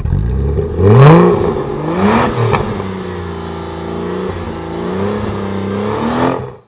motore2.wav